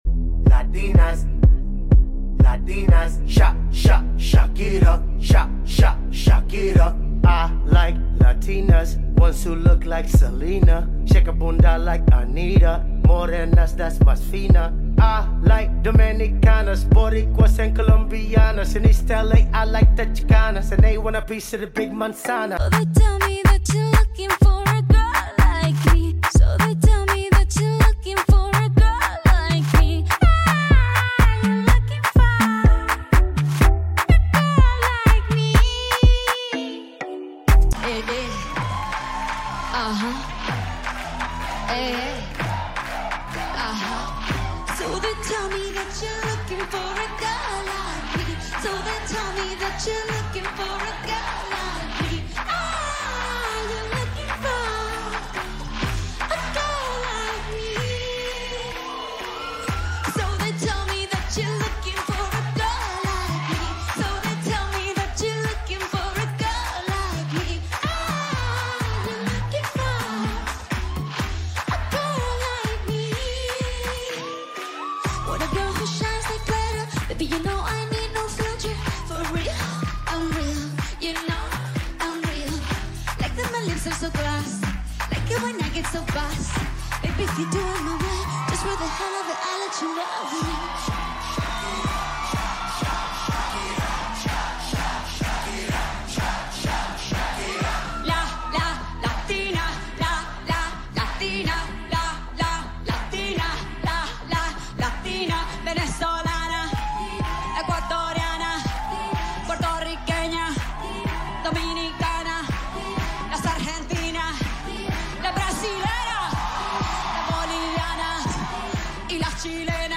sorry for the low quality